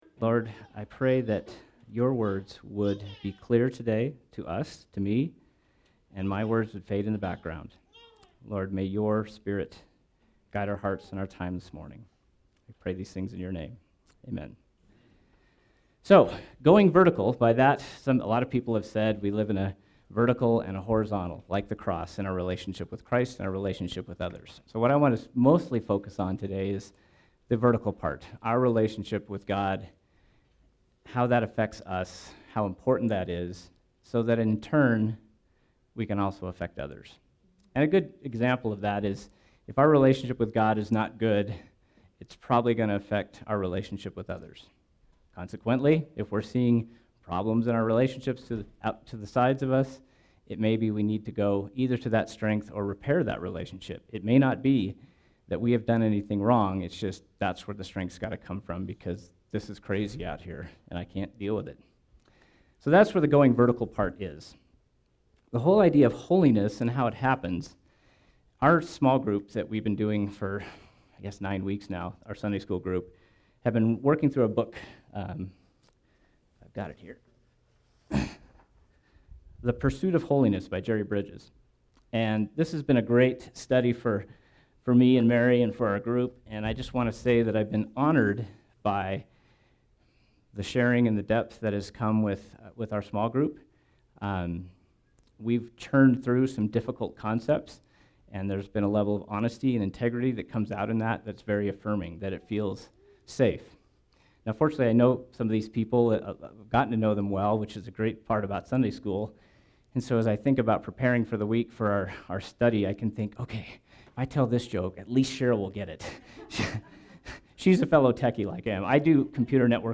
SERMON: Going Vertical